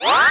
SE_Zoom.wav